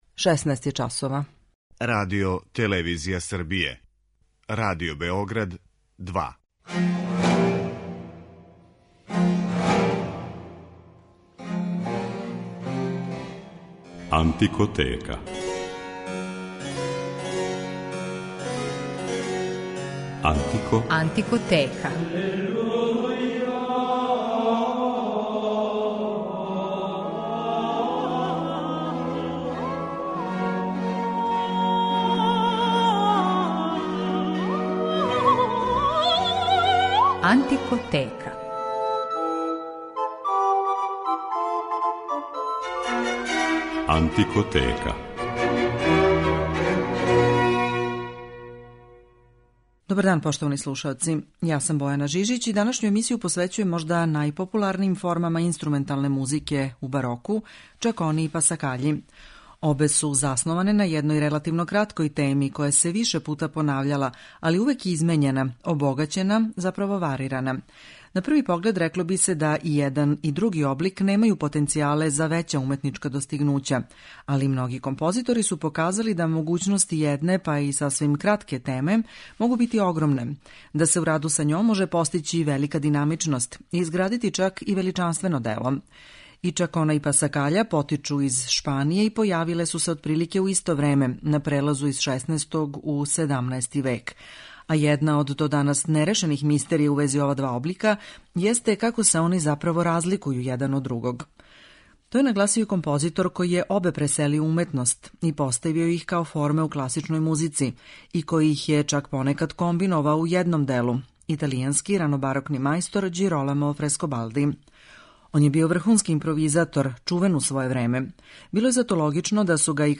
У данашњој Антикотеци од 16 часова моћи ћете да чујете како су чакону и пасакаљу музички обликовали неки од највећих барокних мајстора.